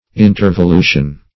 \in`ter*vo*lu"tion\